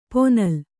♪ ponal